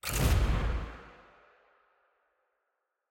sfx-jfe-ui-landing.ogg